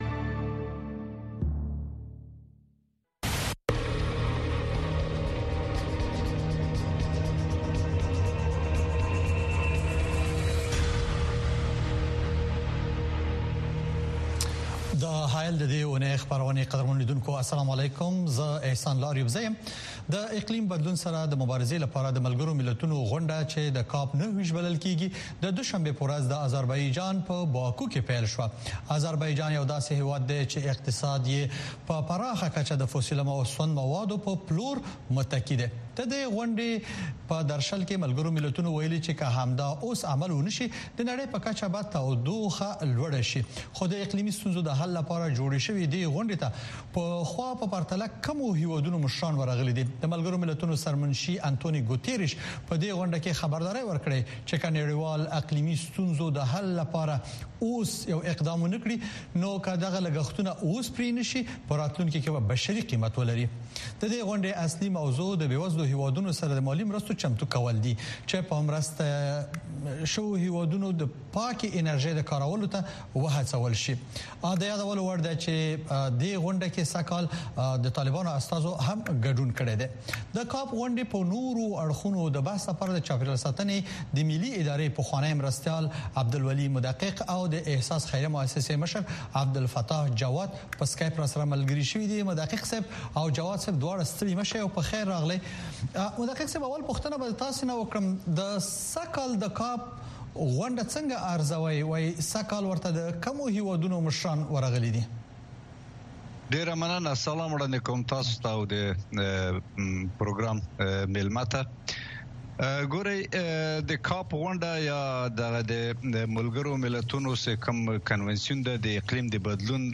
کاوشگری و ژرف نگری رویدادهای داغ روز در افغانستان زیر سلطۀ طالبان را در برنامۀ حایل از صدای امریکا دنبال کنید. وضعیت افغانستان، چگونگی رویدادها، بحث در مورد راه‌حل مشکلات و بن بست‌های موجود در آن کشور از زبان کارشناسان، تحلیلگران و مسوولان هر جمعه شب ساعت ۷:۳۰ به وقت افغانستان